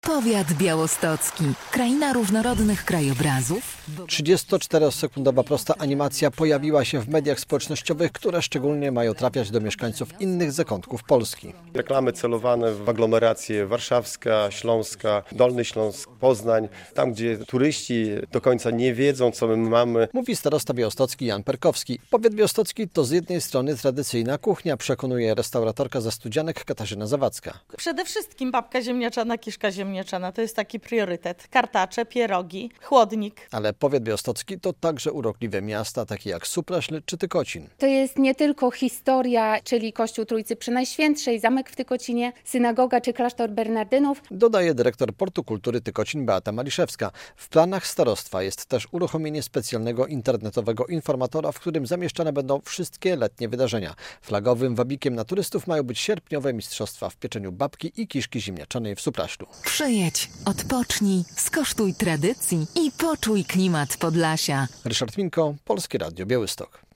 Akcja promocyjna powiatu białostockiego - relacja